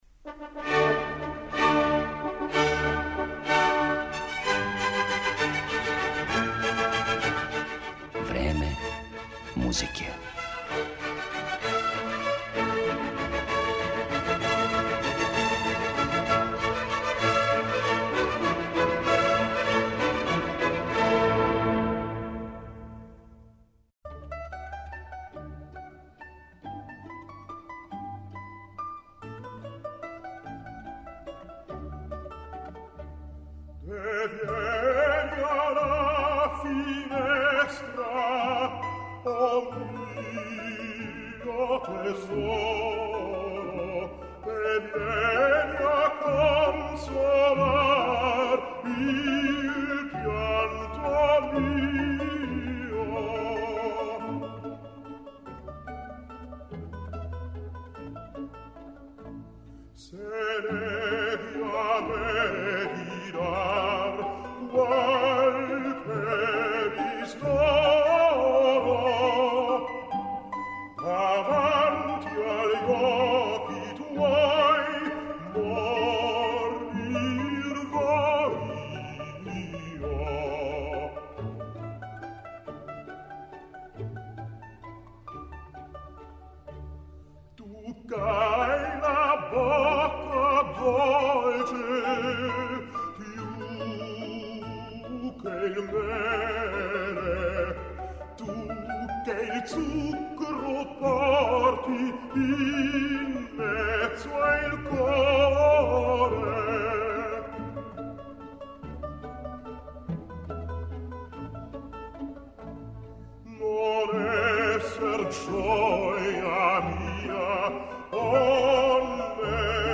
Арије које то нису
испуниће арије које то нису, тј. неке друге вокалне форме које су или специфично оперске или су преузете из традиционалне музике или из свакодневног живота, попут серенада на пример